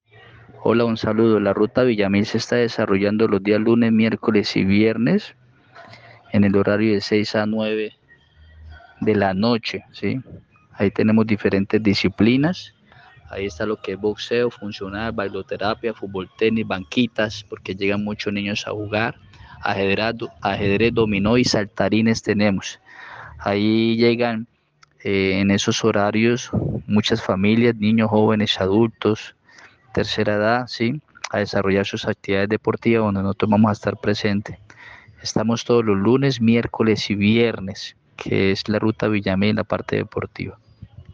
Uver Almanza, Secretario de Deportes y Recreación.mp3